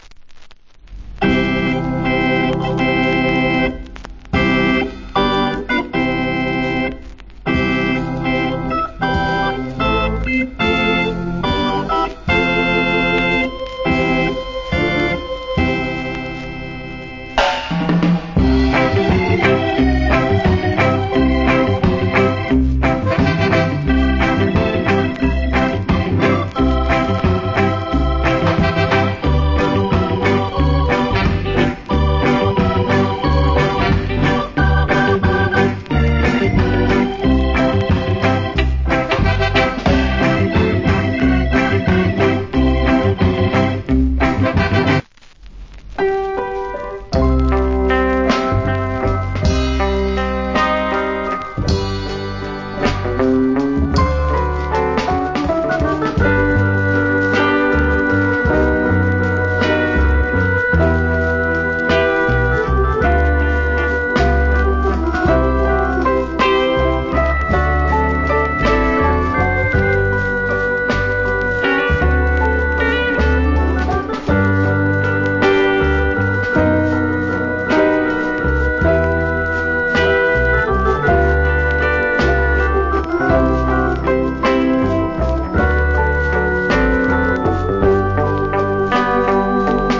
Nice Rock Steady Inst.